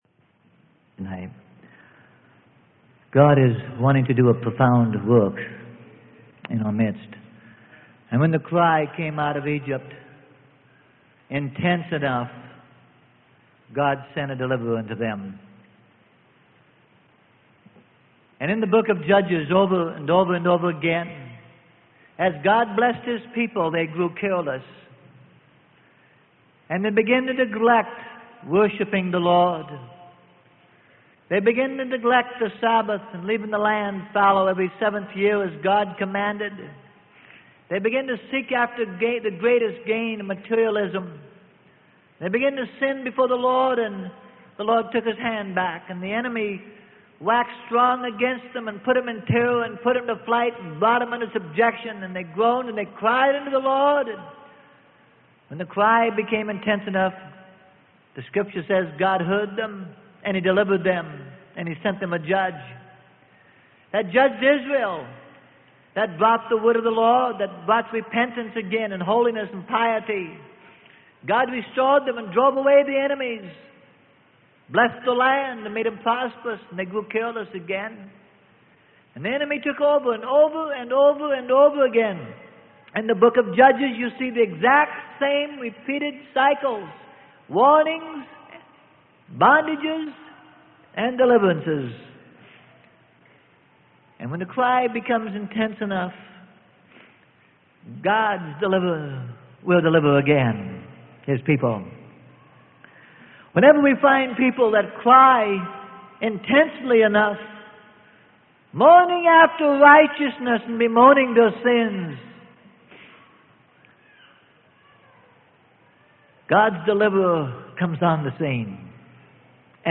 Sermon: The Second Time Is It - Be Ready.